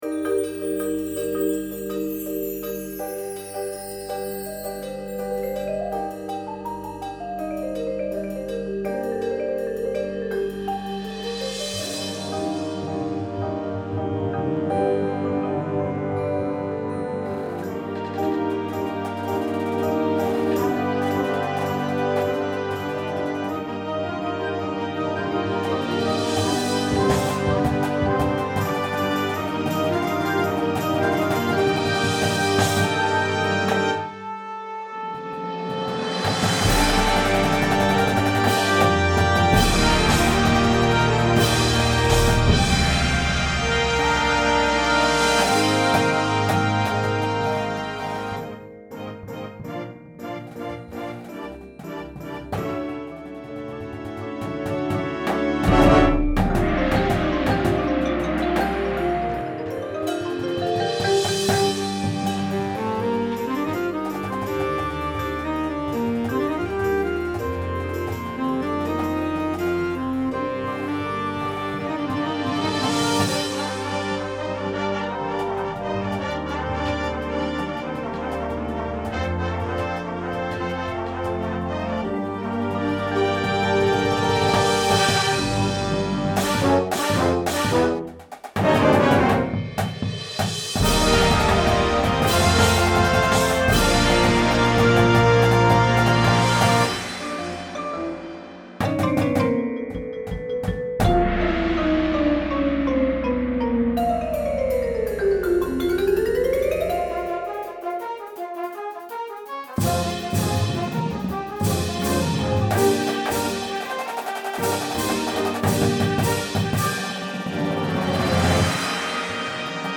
Difficulty: Medium
• Flute
• Trumpet 1
• Tuba
• Snare Drum
• Bass Drums
• Front Ensemble